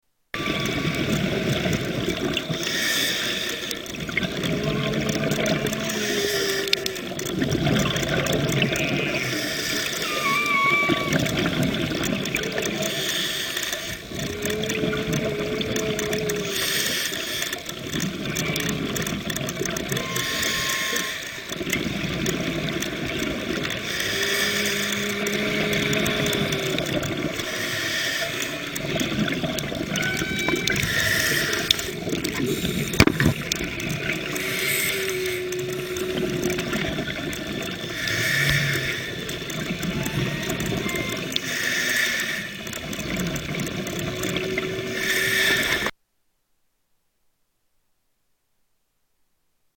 With whale sound
Category: Animals/Nature   Right: Personal